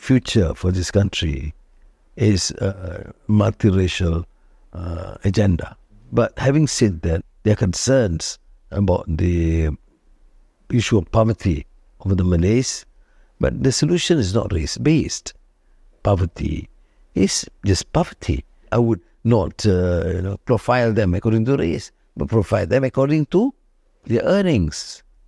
Audio sample of Anwar Ibrahim's voice from one of his interviews
The audio clip provides readers with an authentic representation of Anwar Ibrahim's voice, accent, and speaking style, which contributes to a fuller understanding of his public persona and oratory style in the context of his political career.
Anwar_Ibrahim_Audio_Sample.ogg.mp3